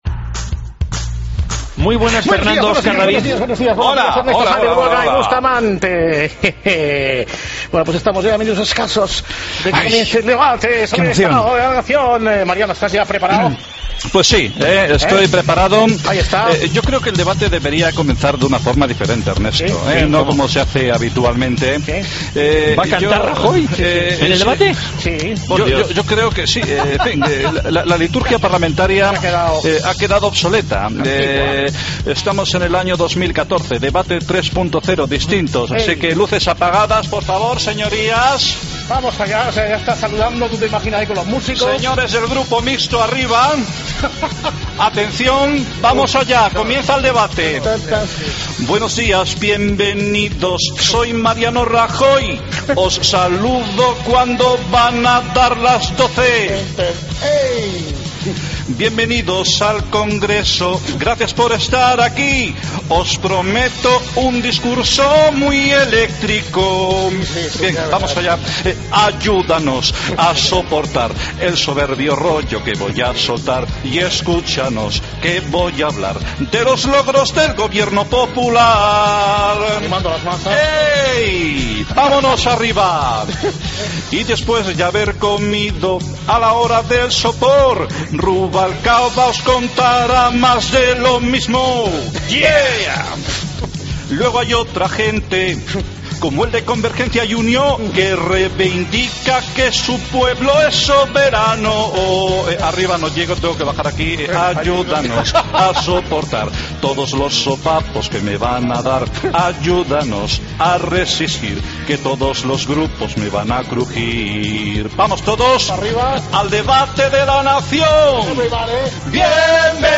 Rajoy canta en el debate